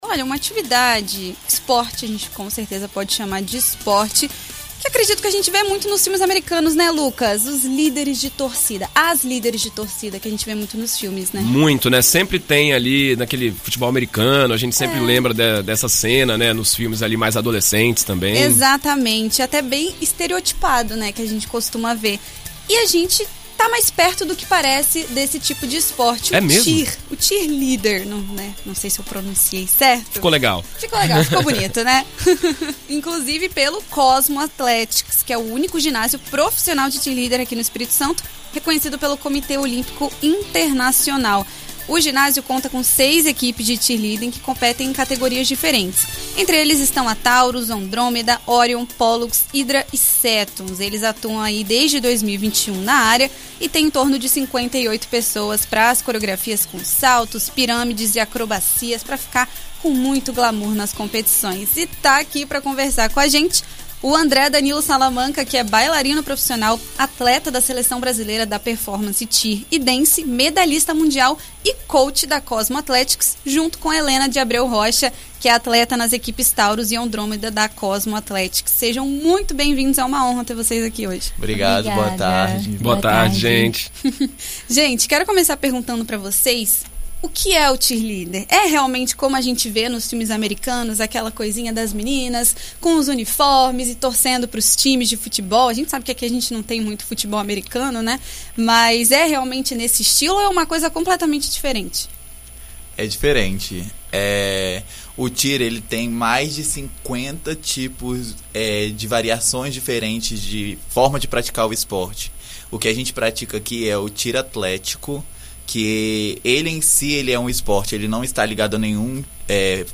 Em entrevista à BandNews FM ES nesta sexta-feira (15)